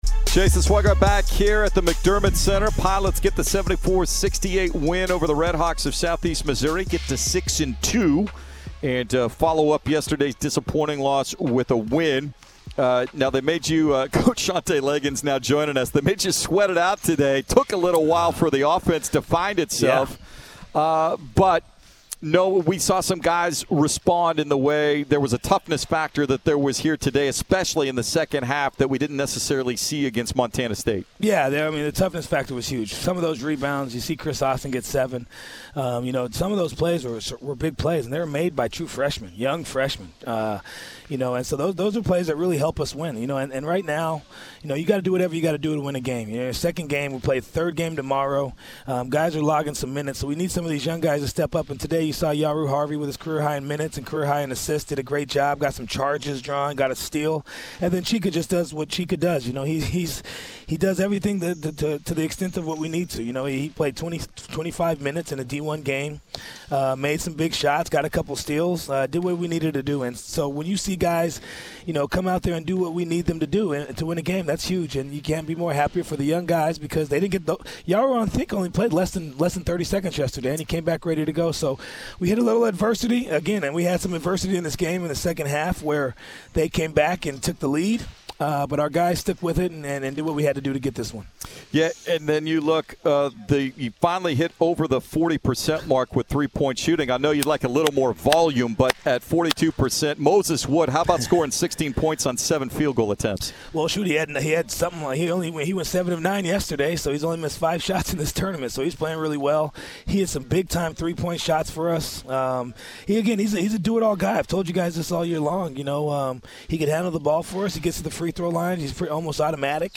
Men's Basketball Post-Game Interview vs. SEMO